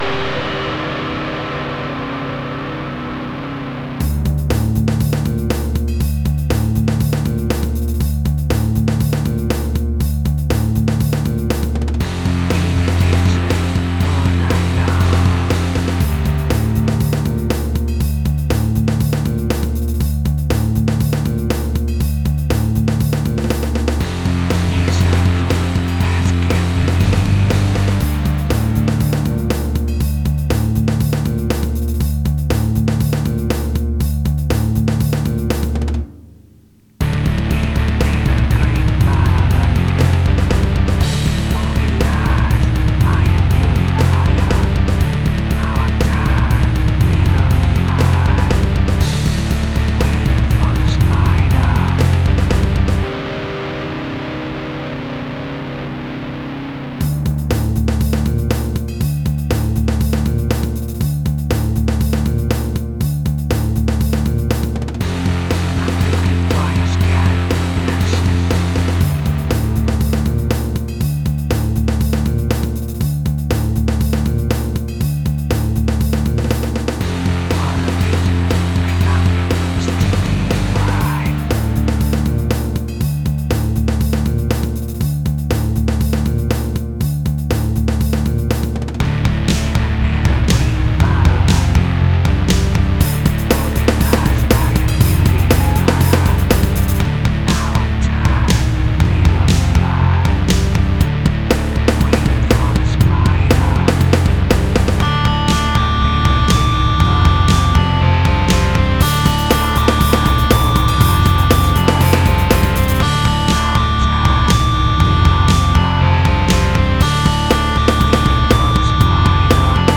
alternative rock
Questo duo